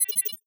NOTIFICATION_Digital_01_mono.wav